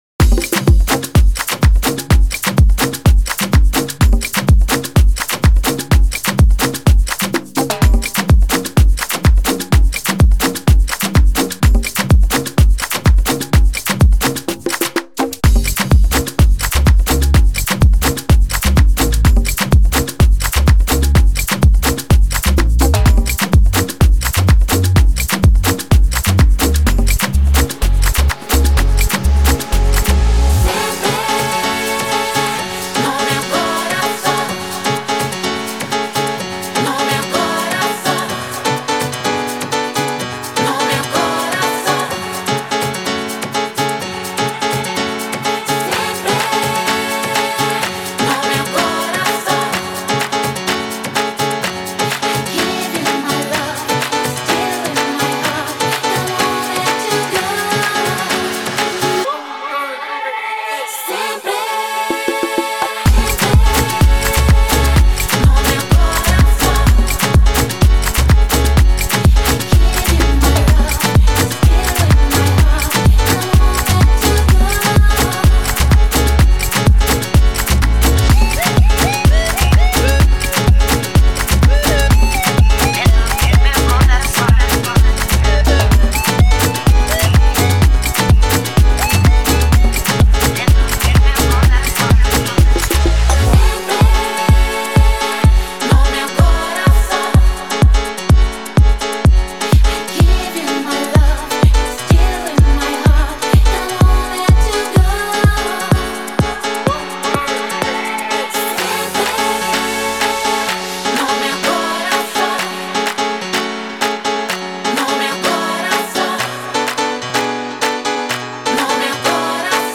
Dance to the Irresistible Afro-Latin Beats in Miami